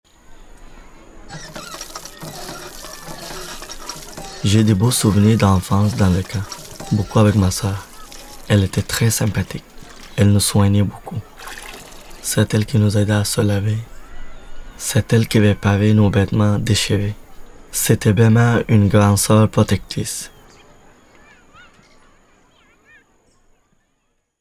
les bruits du camp et ceux de son quotidien actuel — composent un parcours sonore immersif inédit